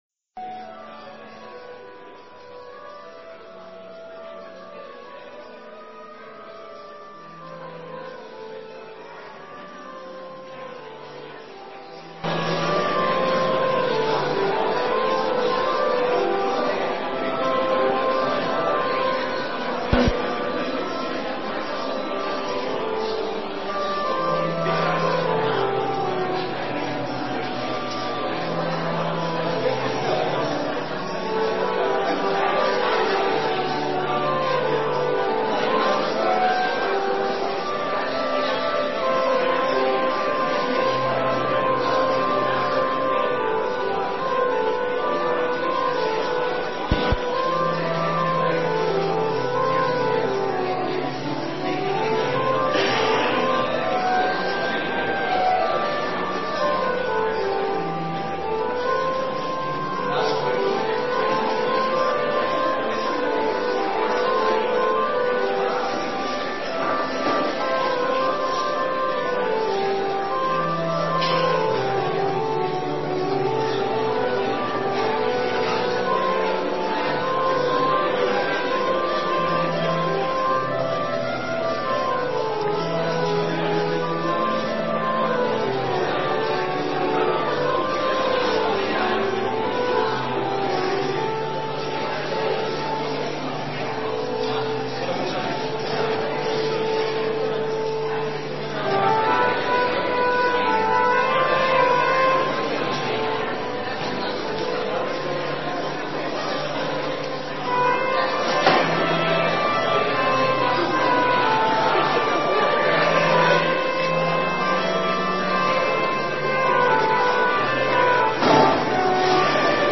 Kerkdienst beluisteren Tekst preek Orde van de dienst